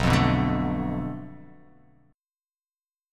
Bm7b5 chord